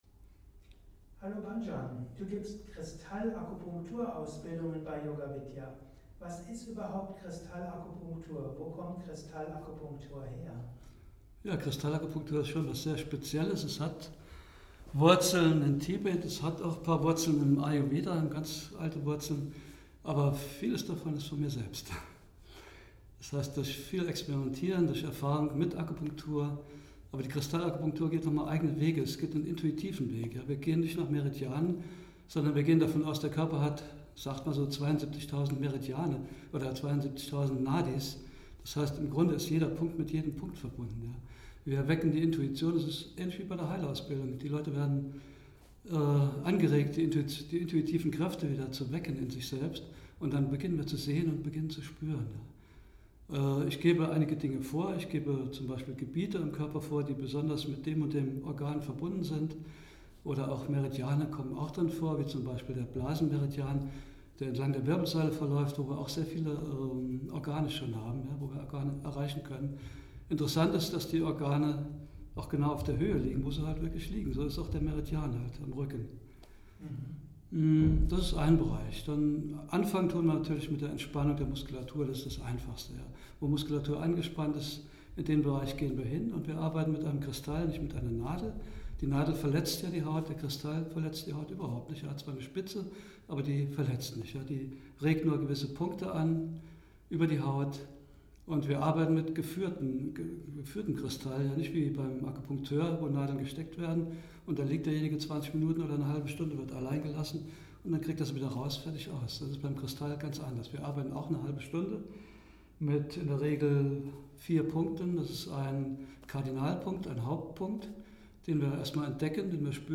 Er antwortet in diesem Interview auf